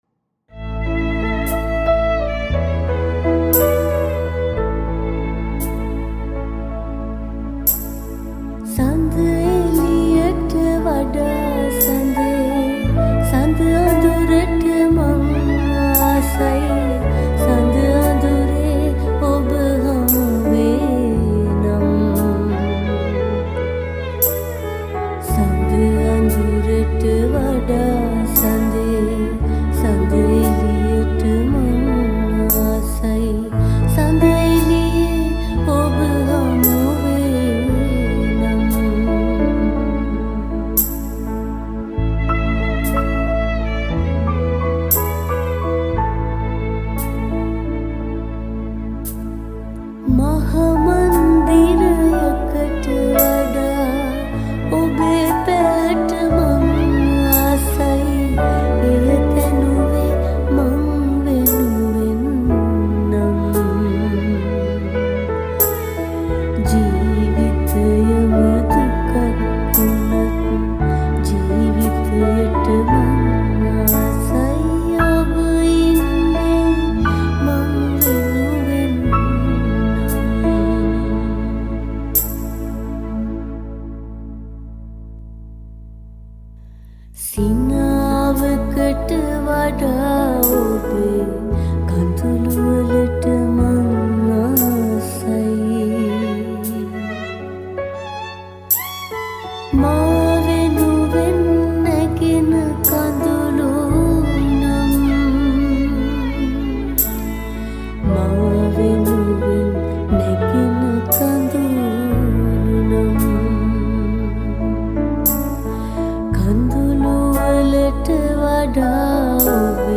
Keys
Violin